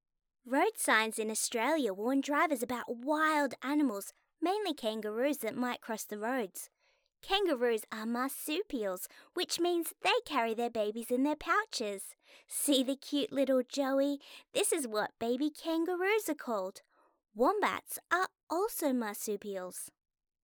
Teenager, Child, Young Adult
Has Own Studio
australian
e-learning
friendly